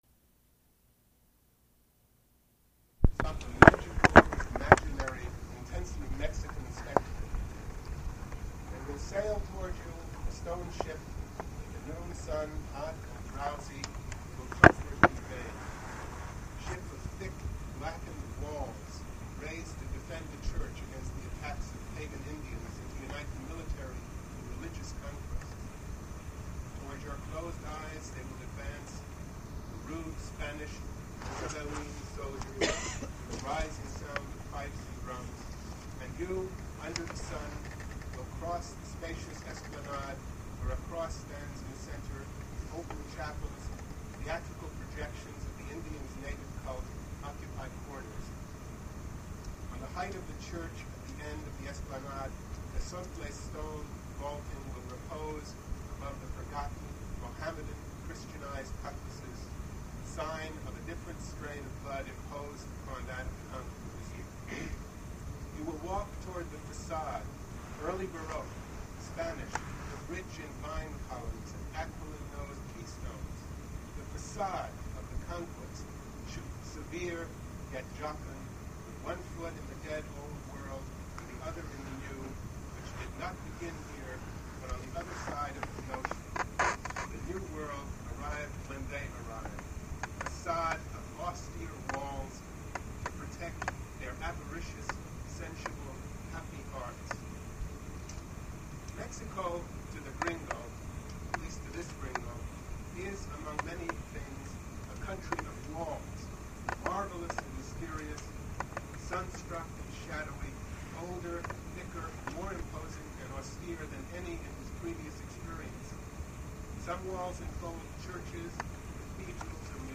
Prose reading featuring Carlos Fuentes
Attributes Attribute Name Values Description Carlos Fuentes reading from his novel, Christopher Unborn.
poor recording quality